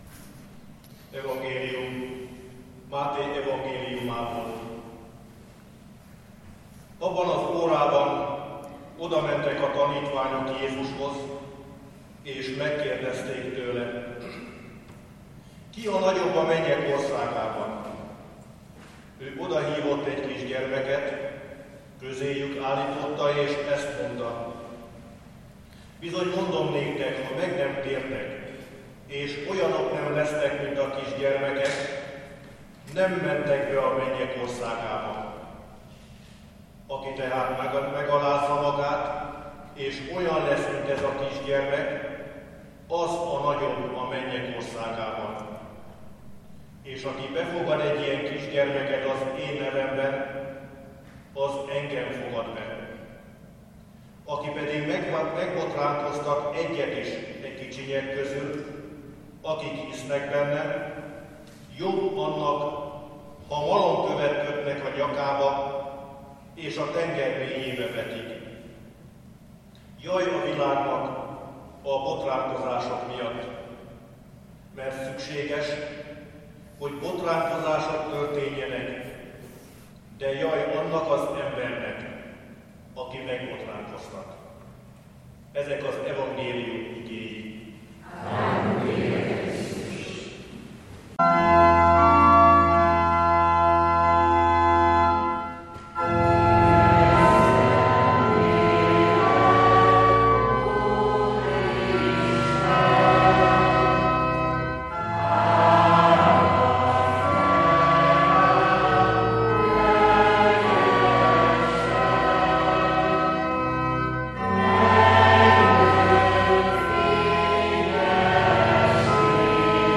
Ökumenikus imahét.